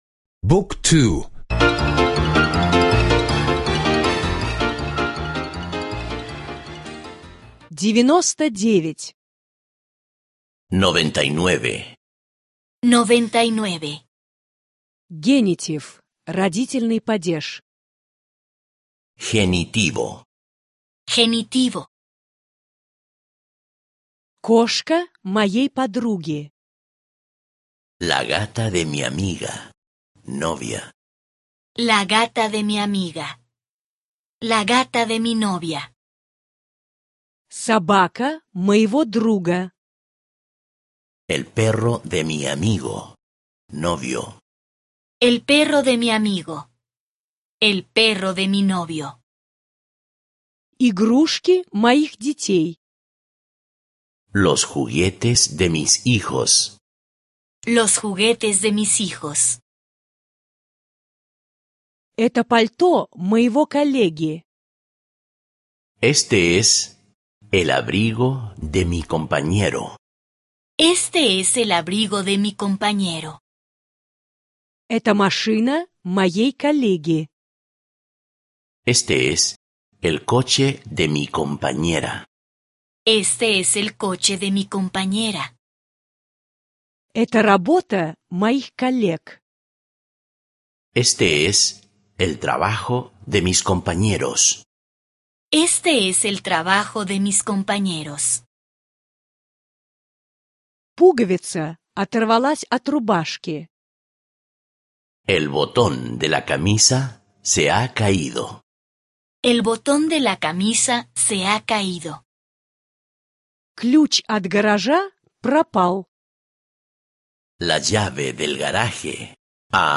Аудио словарь испанского языка — часть 99